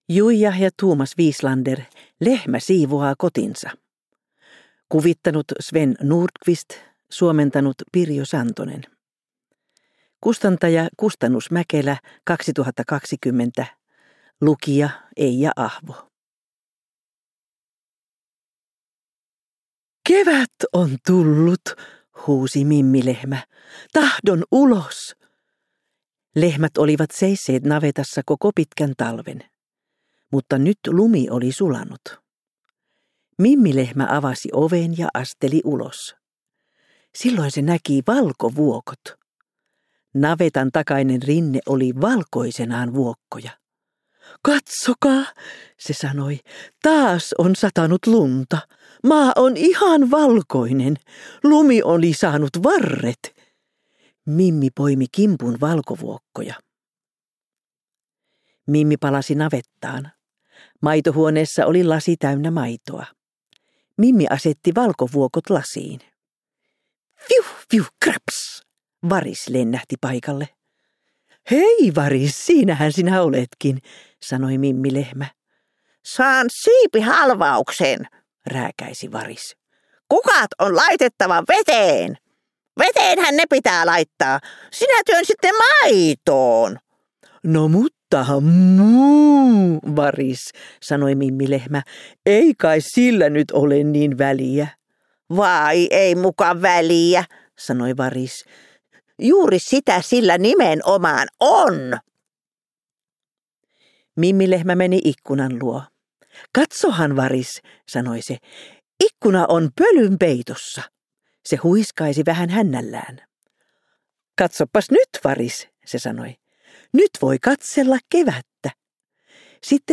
Kuunneltavissa myös äänikirjana useissa eri äänikirjapalveluissa, lukijana Eija Ahvo.